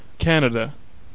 Ääntäminen
US : IPA : [ˈkʰænɪ̈də]